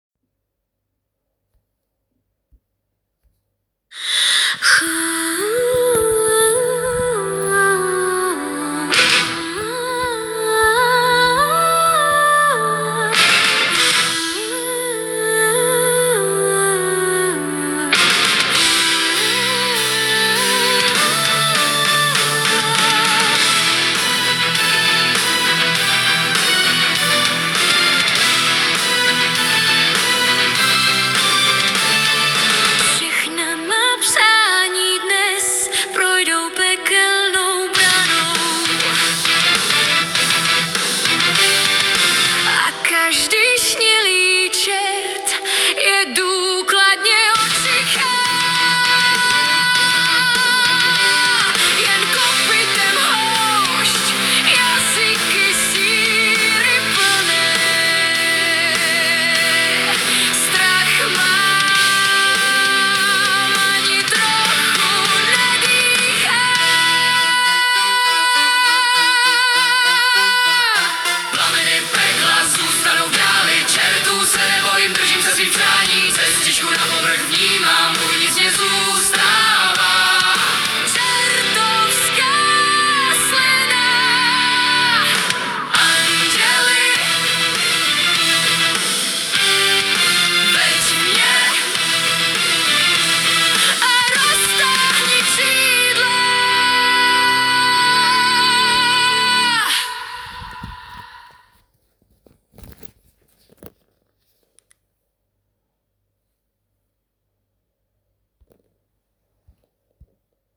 Hudba a zpěv AI
*do Rocku.